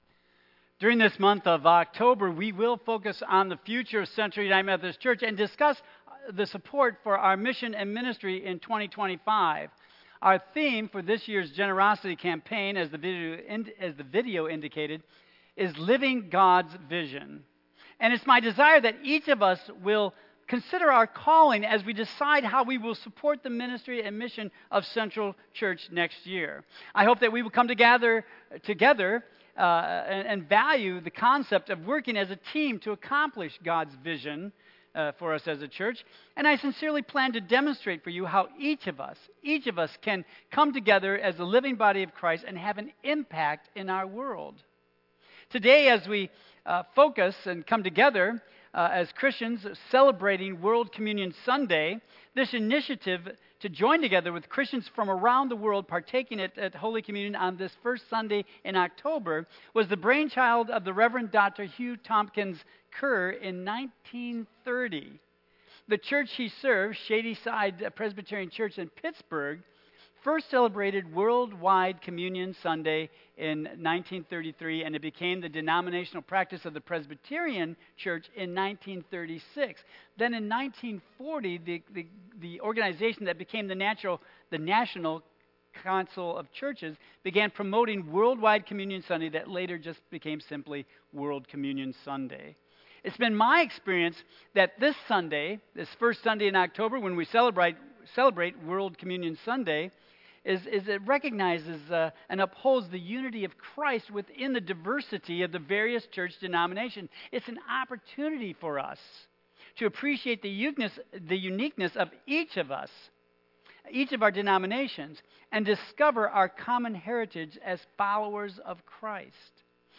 Tagged with Michigan , Sermon , Waterford Central United Methodist Church , Worship Audio (MP3) 9 MB Previous The Bible as a Story of Inclusion Next Your Calling